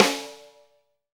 Index of /90_sSampleCDs/Sound & Vision - Gigapack I CD 1 (Roland)/KIT_REAL m 9-12/KIT_Real-Kit m11